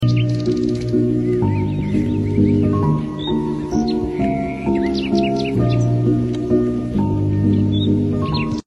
The seaside • sound effects free download